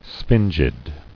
[sphin·gid]